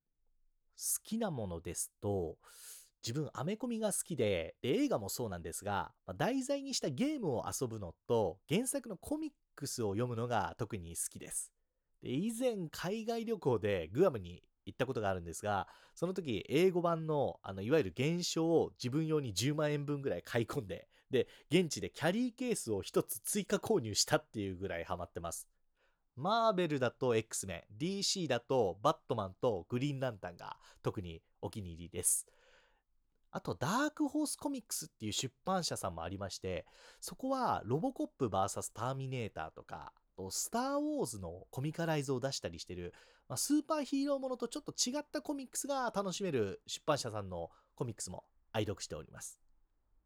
方　言　：　北海道
ボイスサンプル
フリートーク